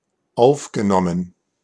Ääntäminen
IPA : /æb.ˈsɔɹbd/